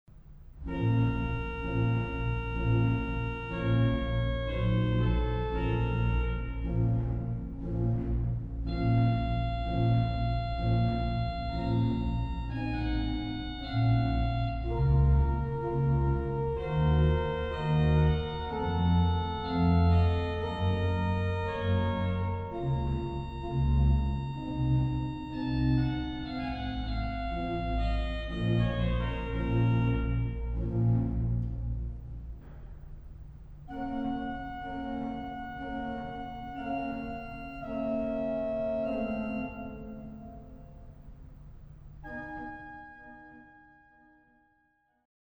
Récit de Trompette accompagné par les Flûtes du Positif et de la Pédale, auquel s’ajoute parfois un contre-chant sur les Fonds 16, 8 du Grand-orgue 1 ; Récit de Hautbois du Positif auquel succède celui du Récit, accompagnement sur les Flûtes d’Echo ; bref Trio des deux Hautbois et Pédale de Flûte 4 ; autre Récit de Trompette, d’un caractère différent, annoncé par le même jeu à l’Echo.
Positif : Montre 8, Bourdon 8, 1ère et 2e Flûte 8
G.O. : Bourdon 16, Montre 8, Bourdon-Flûte 8, Flûte 8
Bombarde : Trompette
Récit : Hautbois
Echo : Flûte 8, Bourdon 8
Pédale : Flûte 16, Soubasse 16, Flûte 8
Ce contre-chant destiné à être joué au Grand-orgue avec le pouce de la main droite est exécuté ici par une troisième main en raison de l’inversion des claviers de Récit et de Bombarde.